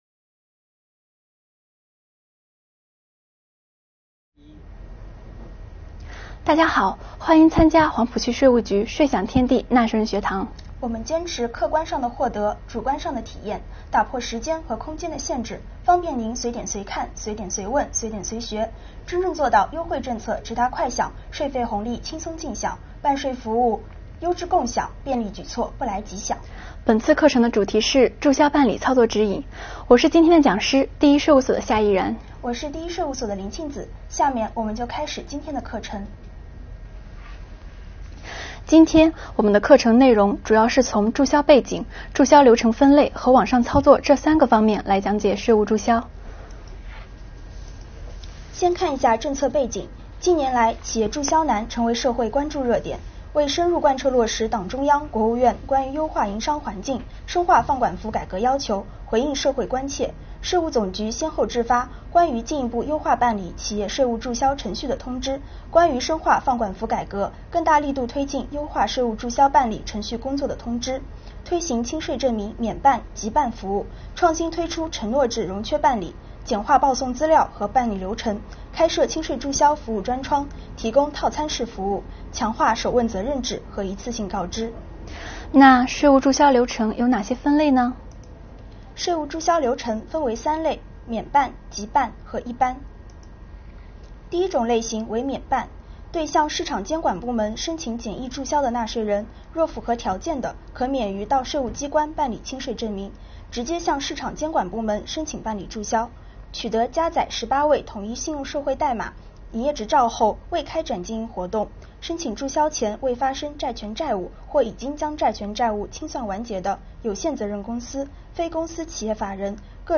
为了帮助企业更好的完成注销办理业务，黄浦税务税享天地开展注销办理操作指引业务培训。